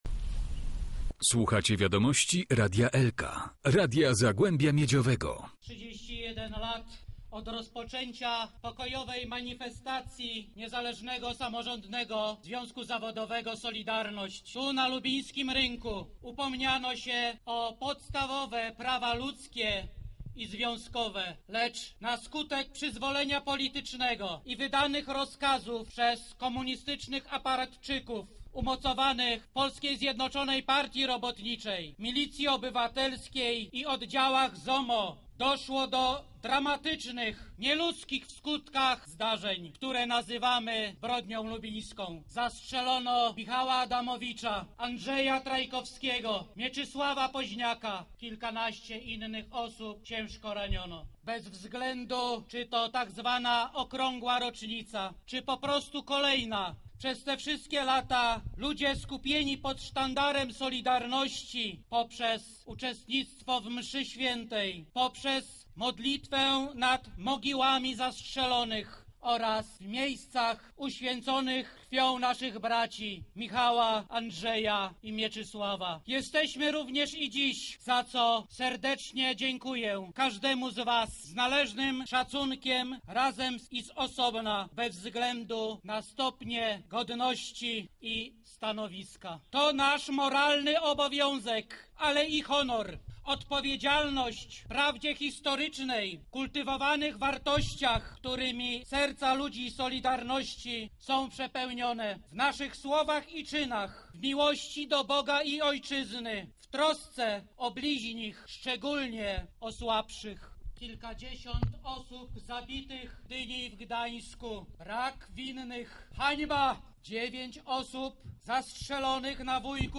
Tak uczczono 31. rocznicę Zbrodni Lubińskiej.